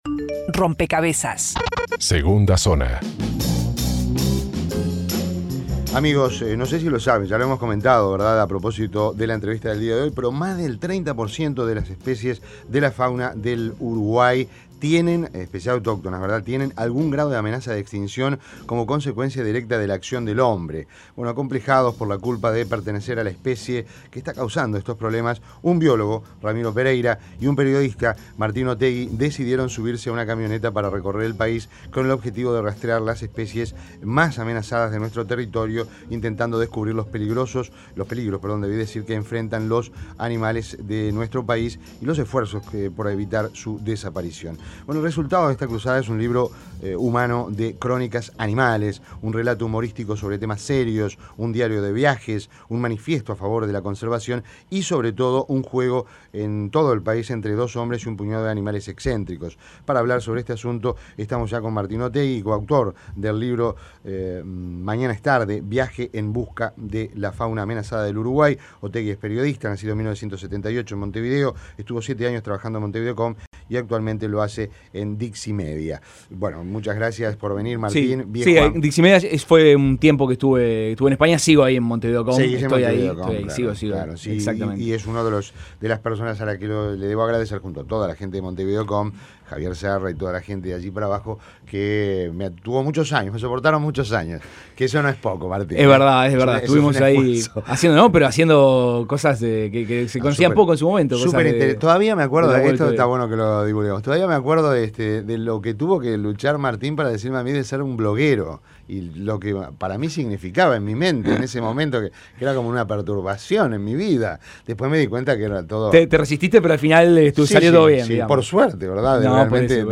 Entrevista Aún hay tiempo, "Mañana es tarde" Aún hay tiempo, "Mañana es tarde" Aún hay tiempo, "Mañana es tarde" Margay.